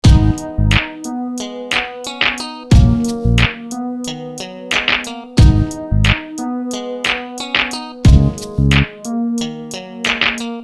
115 MB of R&B loops. 10 construction perfect for that urban smooth R&B sound